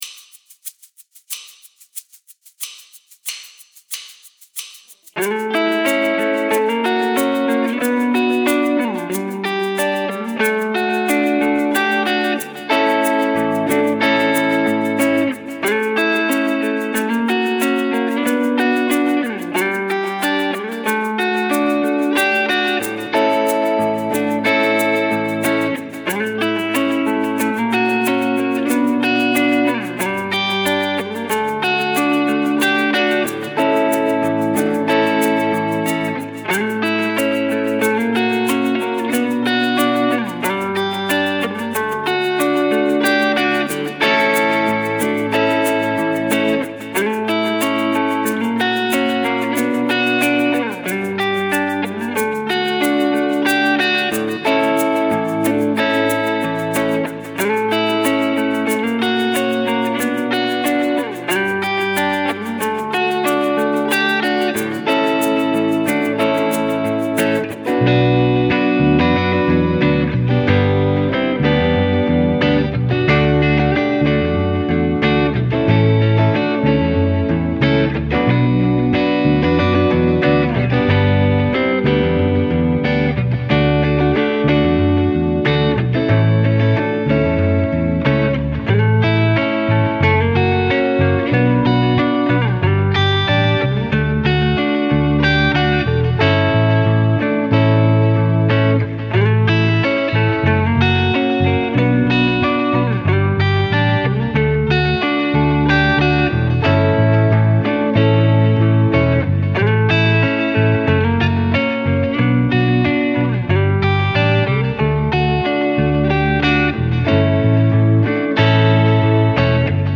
BPM : 92
Tuning : E
Without vocals